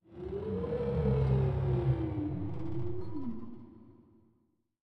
Minecraft Version Minecraft Version snapshot Latest Release | Latest Snapshot snapshot / assets / minecraft / sounds / ambient / nether / crimson_forest / shroom1.ogg Compare With Compare With Latest Release | Latest Snapshot